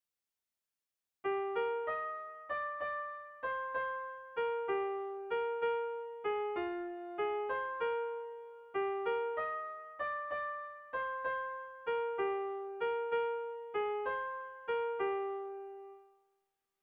A1A2